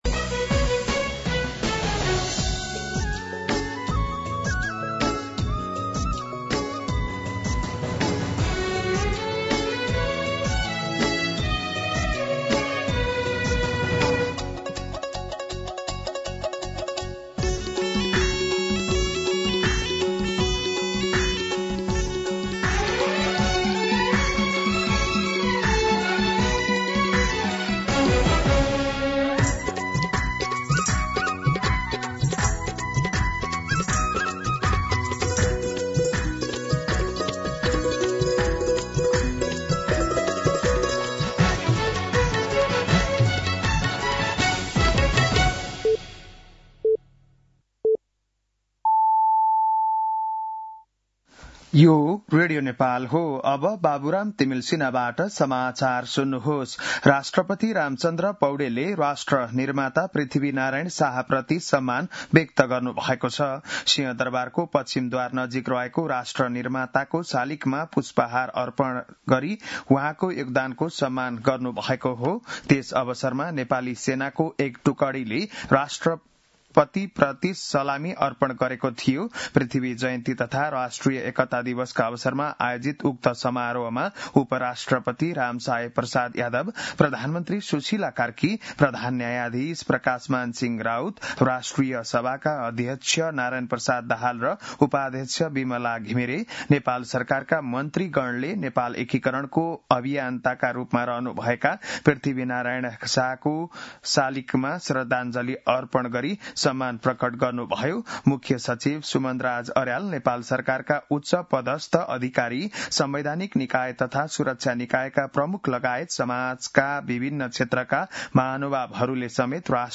बिहान ११ बजेको नेपाली समाचार : २७ पुष , २०८२
11am-News.mp3